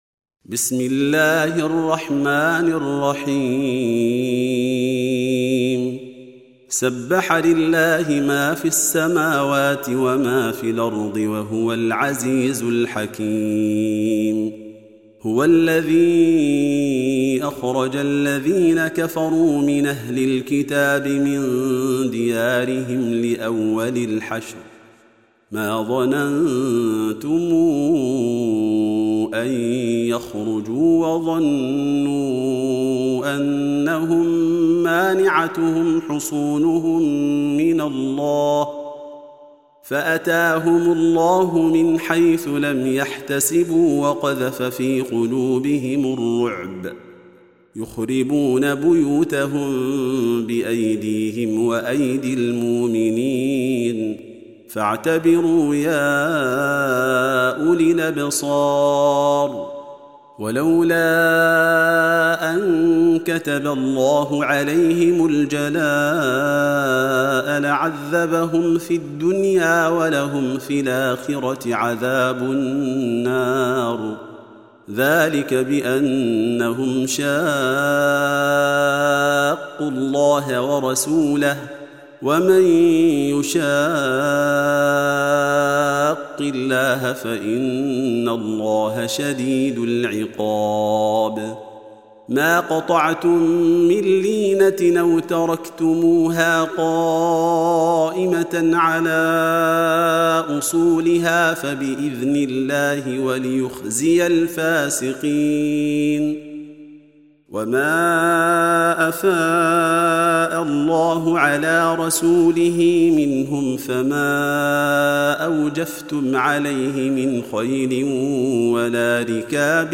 Surah Sequence تتابع السورة Download Surah حمّل السورة Reciting Murattalah Audio for 59. Surah Al-Hashr سورة الحشر N.B *Surah Includes Al-Basmalah Reciters Sequents تتابع التلاوات Reciters Repeats تكرار التلاوات